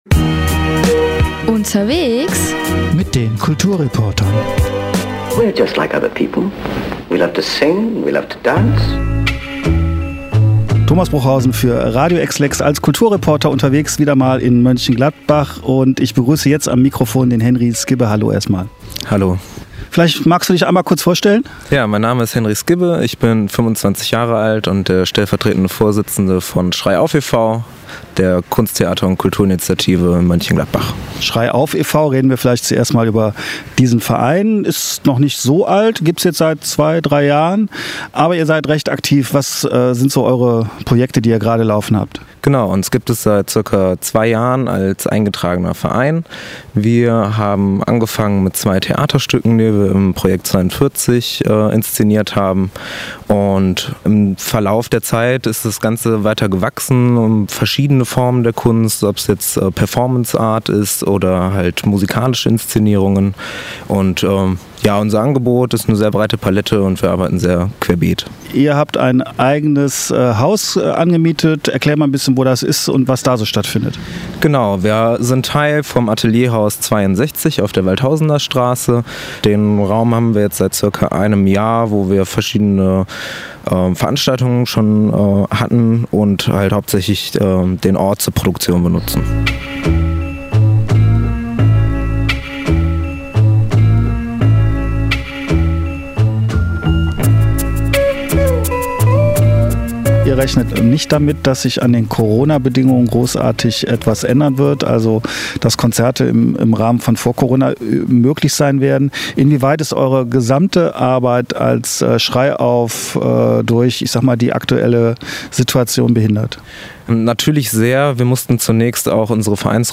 Kulturreporter